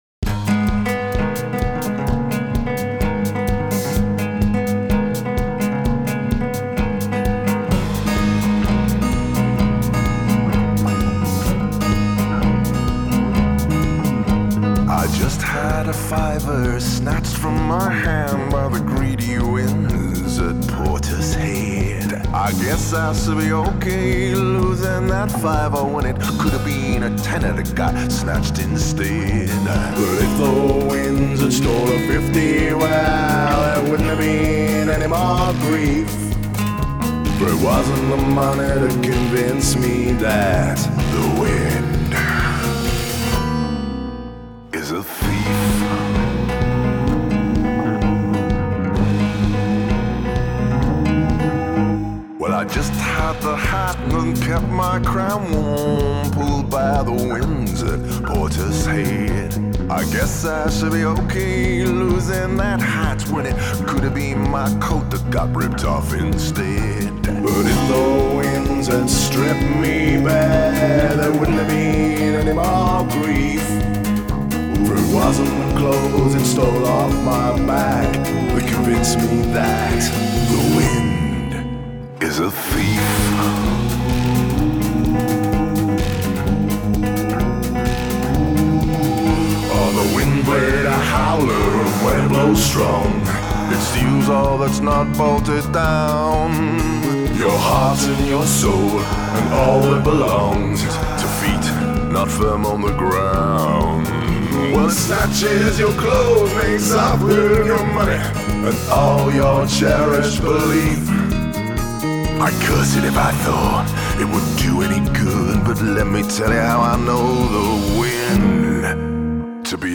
Acoustic Guitars,Electronica,SFX & Voices
Cajon & Assorted Percussion
Recorded & Mixed